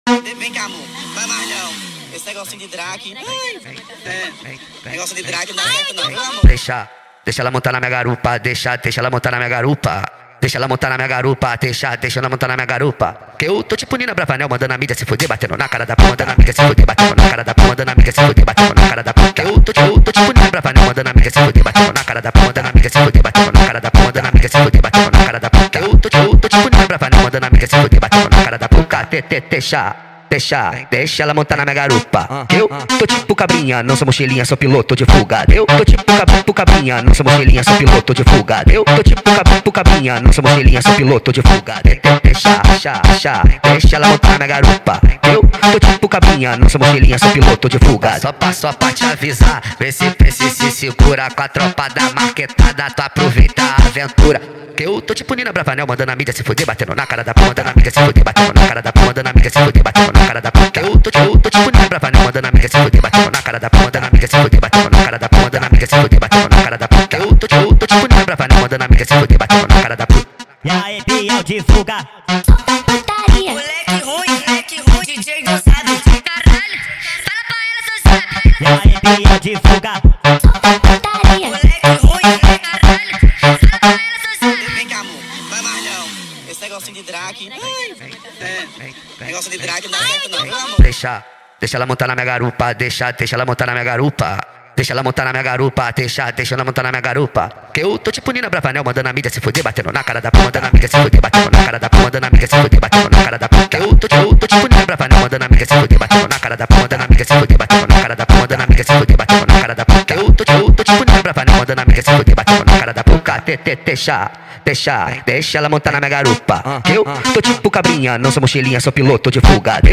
فانک اینستاگرام با ریتم تند
فانک